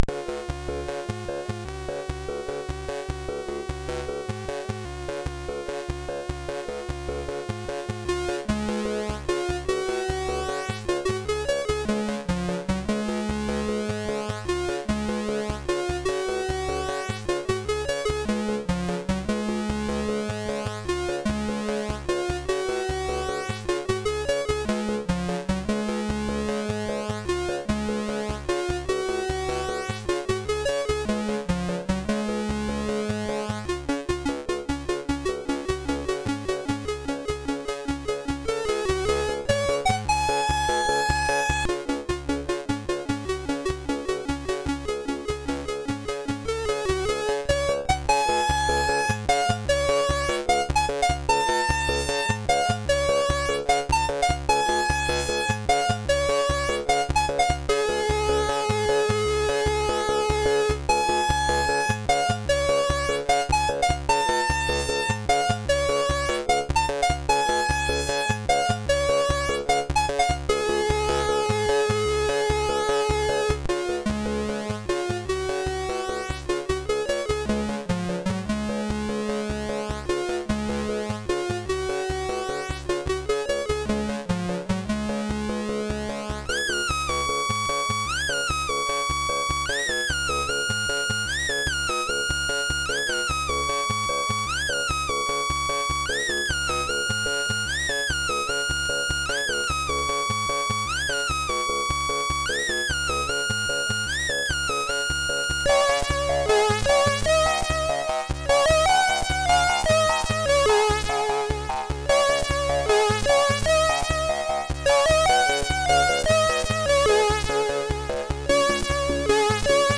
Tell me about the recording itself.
1 channel